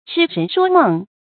痴人说梦 chī rén shuō mèng
痴人说梦发音
成语正音痴，不能读作“zhī”。